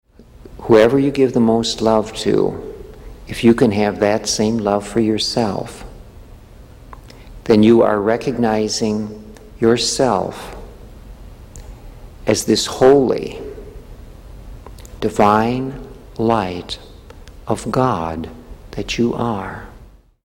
Tags: Eckankar Soundboard Eckankar Eckankar Soundclips HU song Eckankar Cult